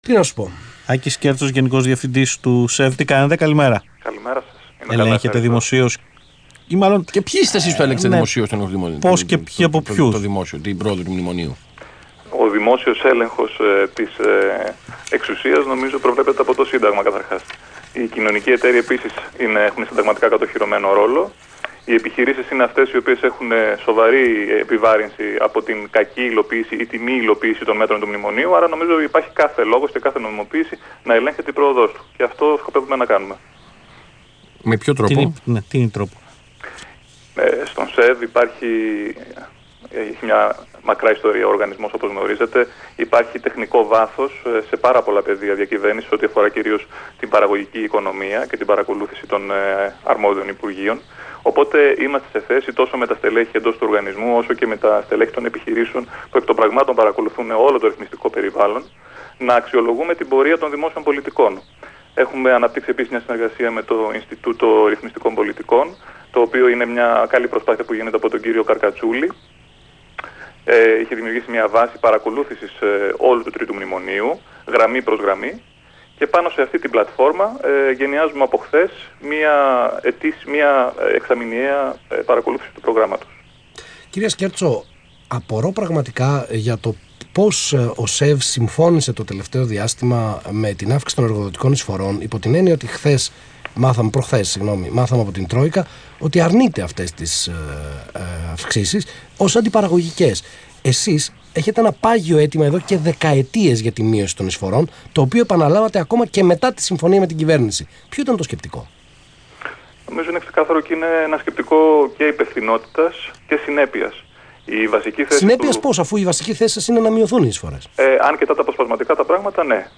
Συνέντευξη του Γενικού Διευθυντή του ΣΕΒ, κ. Άκη Σκέρτσου στον Ρ/Σ Αθήνα 9.84, 5/2/16